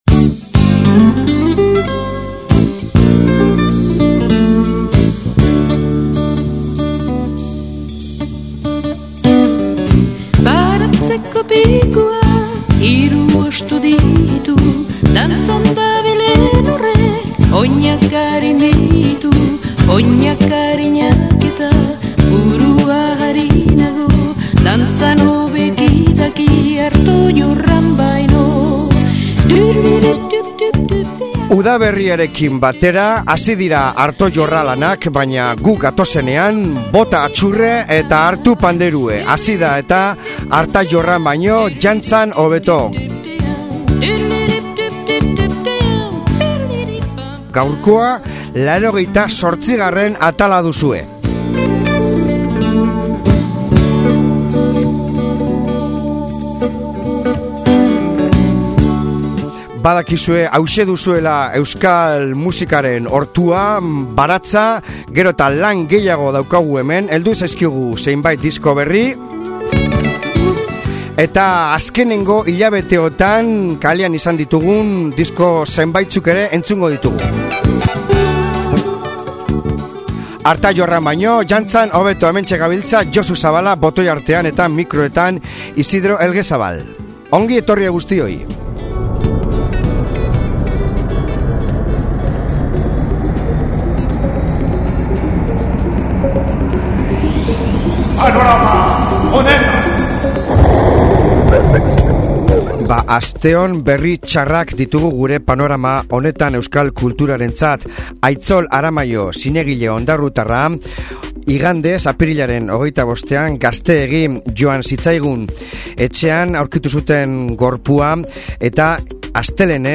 Indiatiko oihartzunez beteta